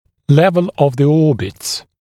[‘levl əv ðə ‘ɔːbɪts][‘лэвл ов зэ ‘о:битс]уровень верхнего края глазниц